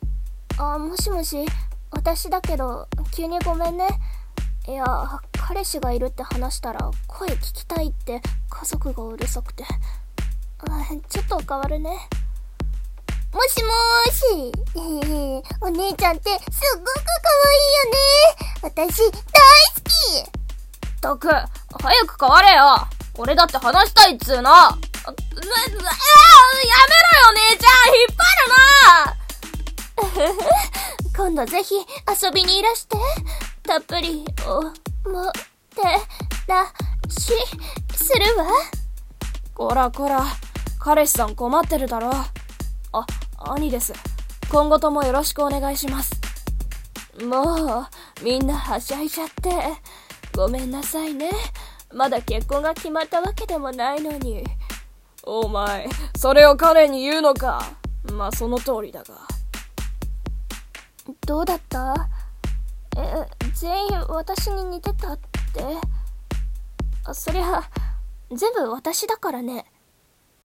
【演じ分け台本】声帯家族紹介【4/10の挑戦状】